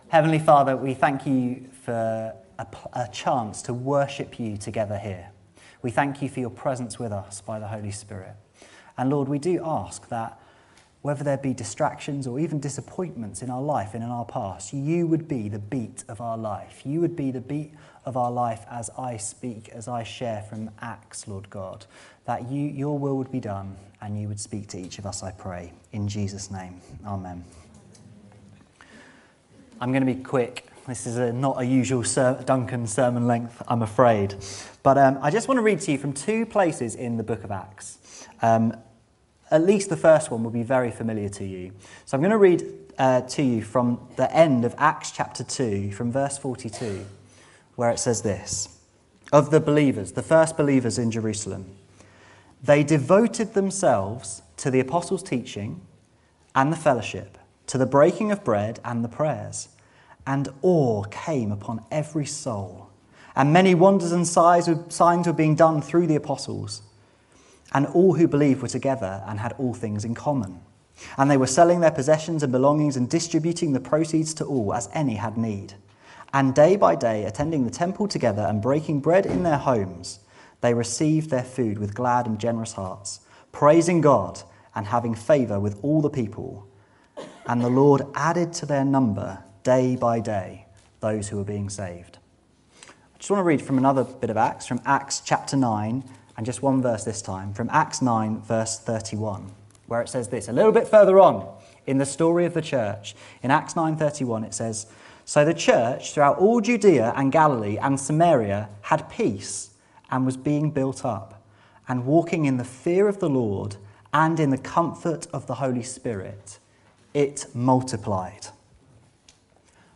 This sermon is a call to imitate the devotion displayed by the Church in Acts.